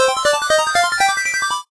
8bit_lead_vo_03.ogg